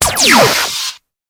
ray.wav